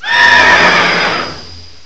sovereignx/sound/direct_sound_samples/cries/spectrier.aif at master